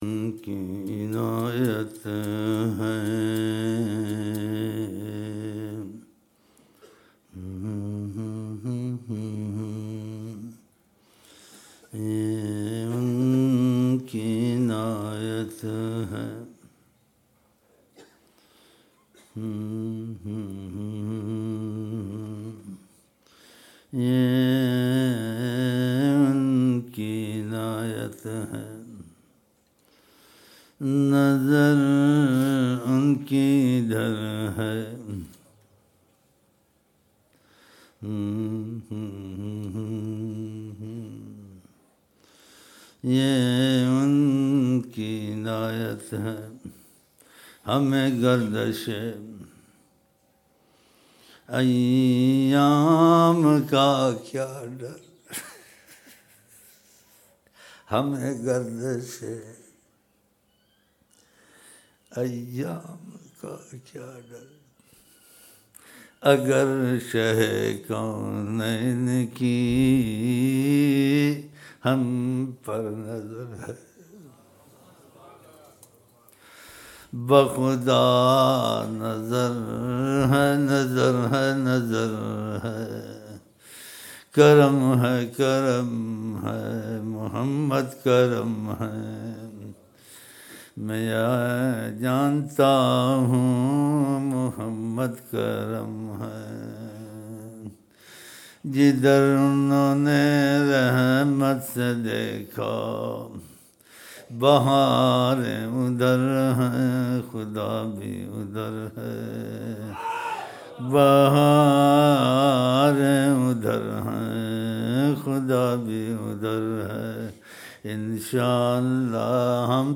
5 January 2007 Friday Jumma Mehfil (14 Dhu al-Hijjah 1427 AH)
Dua- 22 minutes Hamd(Tu rehmat pe aaye to isyaan dhulen sab, Tawaif jo kutte ko paani pila de, Hain Rahman Aap, Raheem Ilahi)5:30 Minutes